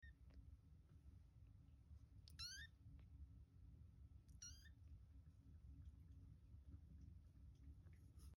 Foster Kittens At About Two Sound Effects Free Download